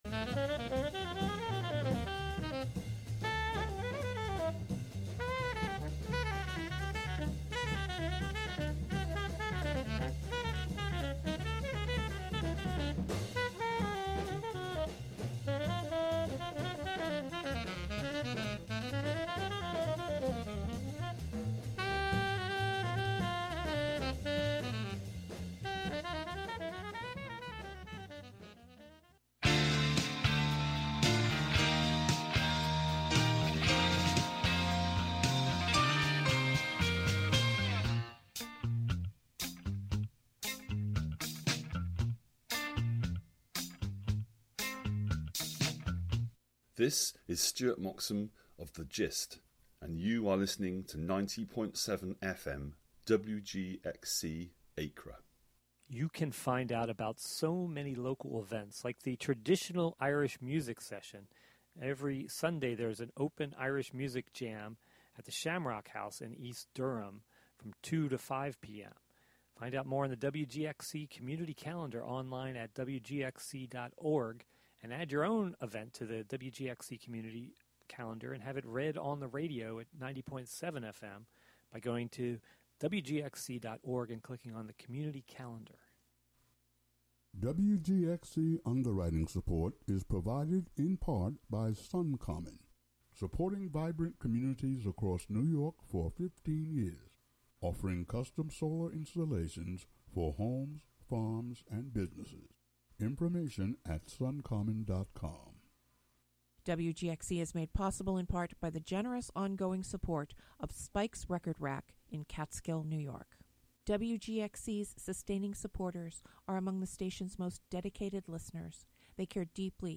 The tape still exists and we'll play the whole thing and a new recording of his memories of that evening. Plus, even more organ music.